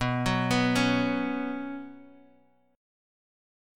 Bsus2 chord